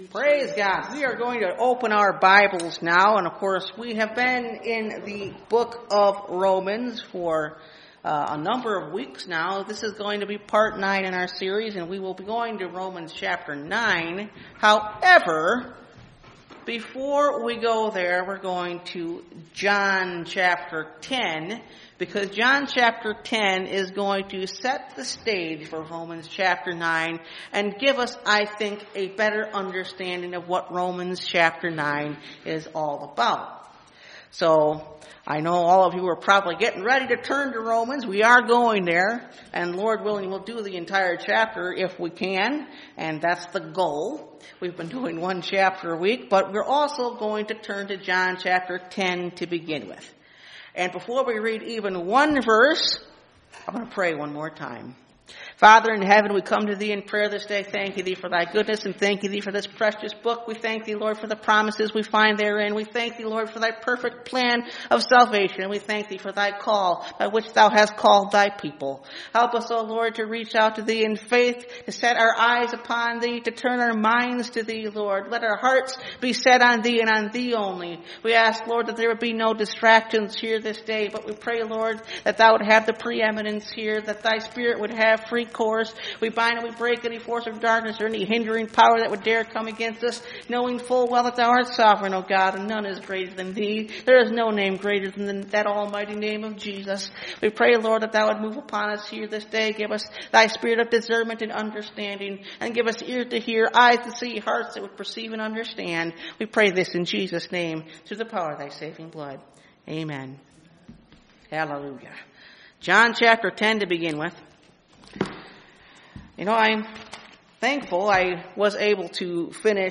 The Book Of Romans – Part 9 (Message Audio) – Last Trumpet Ministries – Truth Tabernacle – Sermon Library